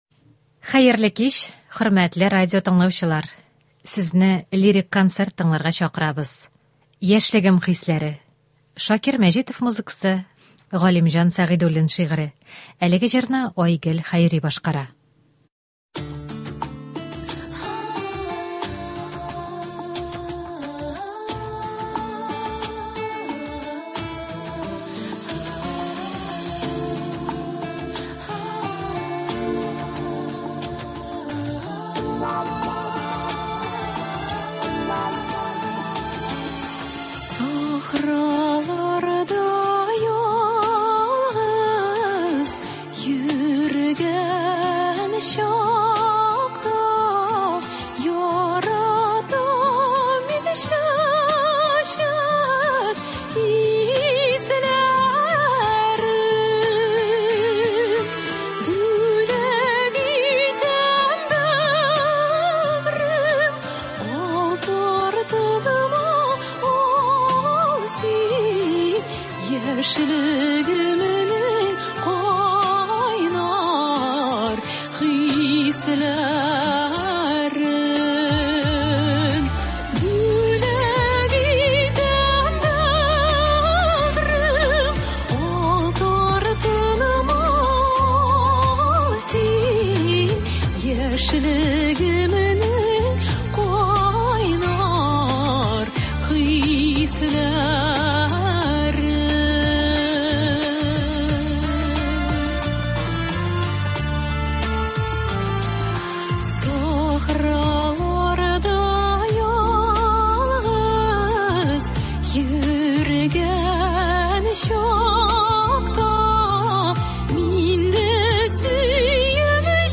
Көндезге концерт. Кичке концерт. Лирик концерт.